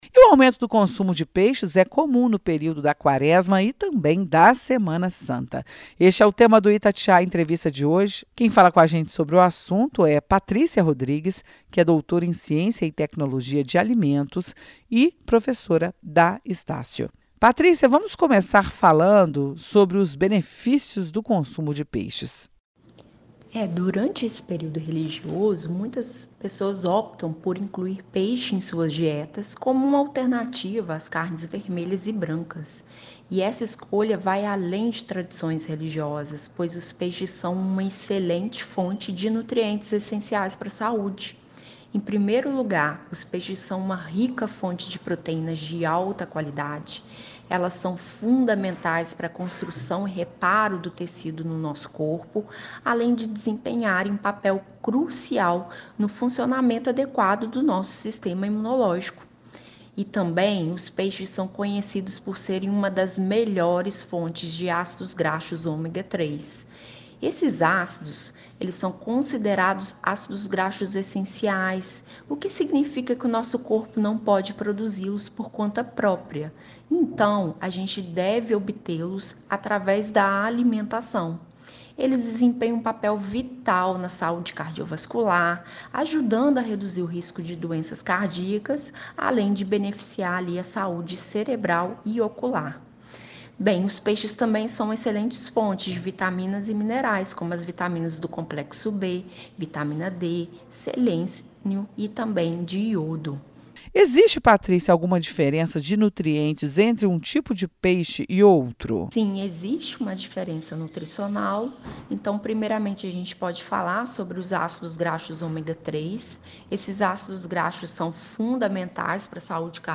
ITATIAIA-ENTREVISTA_CONSUMO-PEIXES.mp3